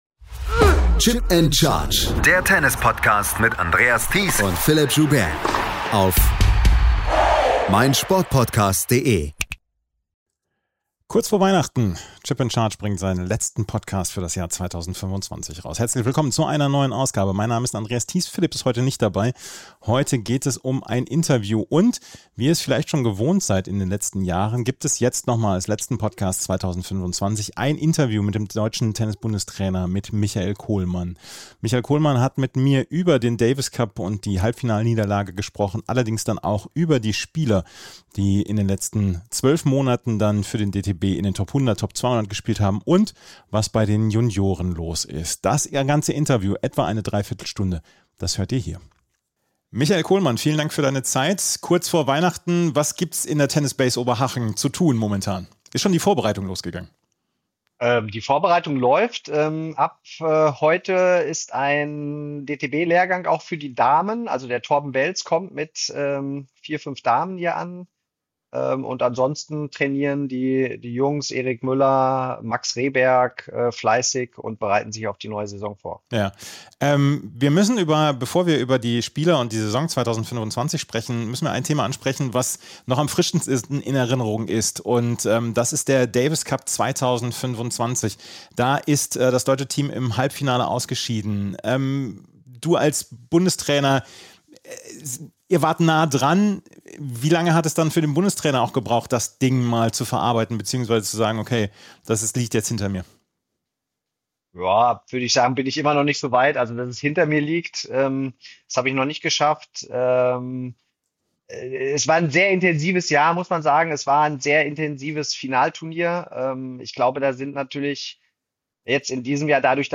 Willkommen zur 98. und letzten Episode von Chip & Charge 2025 - heute mit einem ausführlichen Interview mit Michael Kohlmann.